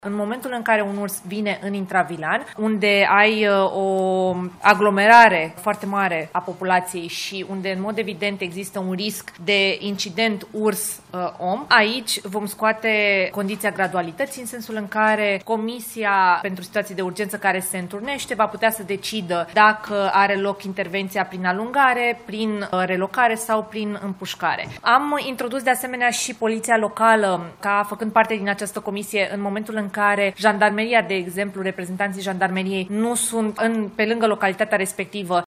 Ministra Mediului, Diana Buzoianu a precizat, la finalul ședinței de guvern, că în afara localităților, se menține aplicarea graduală a măsurilor de intervenție în cazul urșilor.
Ministra Mediului, Diana Buzoianu: „Comisia pentru situații de urgență care se întrunețte va putea să decidă dacă are loc intervenția prin alungare, prin relocare sau prin împușcare”